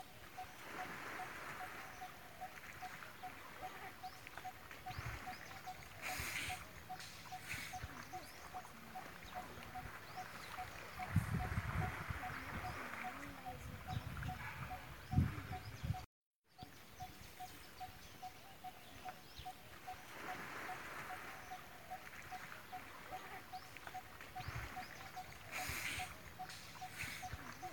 Barbudo Calderero (Psilopogon haemacephalus)
Nombre en inglés: Coppersmith Barbet
País: Tailandia
Localización detallada: Elephant Valley
Condición: Silvestre
Certeza: Vocalización Grabada